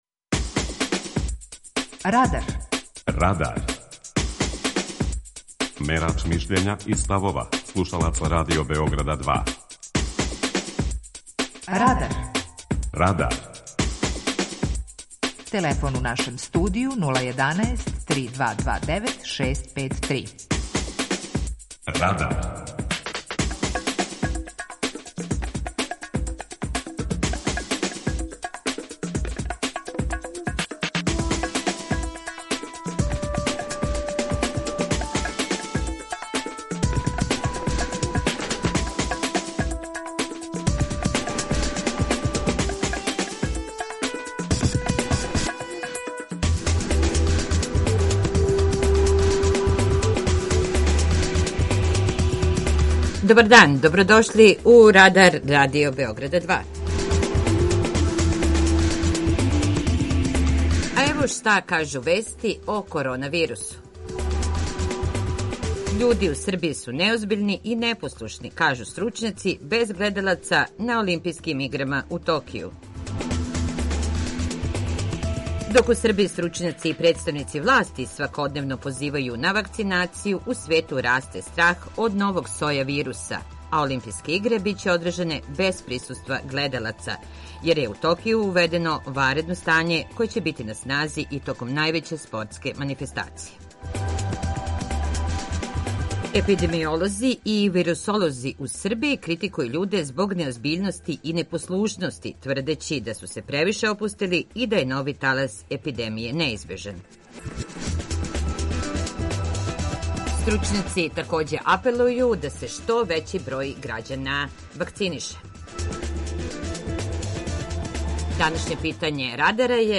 Питање Радара: Да ли треба увести обавезну вакцинацију против короне? преузми : 18.46 MB Радар Autor: Група аутора У емисији „Радар", гости и слушаоци разговарају о актуелним темама из друштвеног и културног живота.